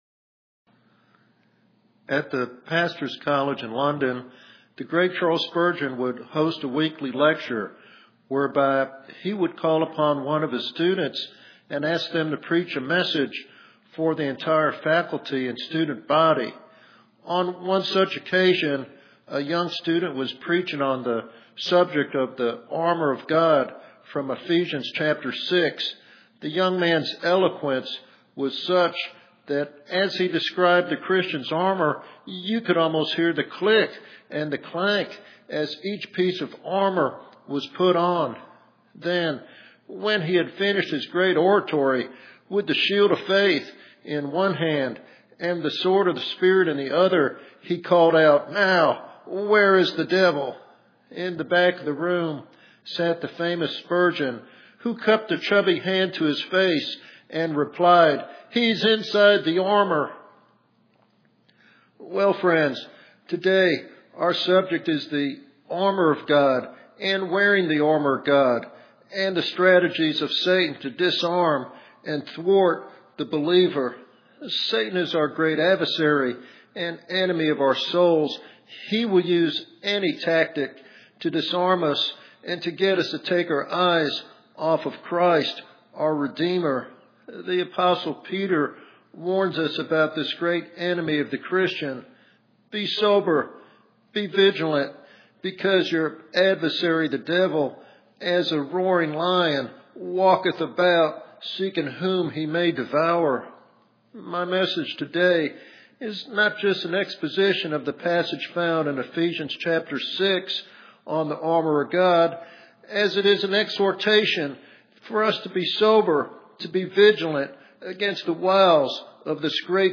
This sermon offers practical insights and spiritual encouragement for all believers seeking strength in their walk with Christ.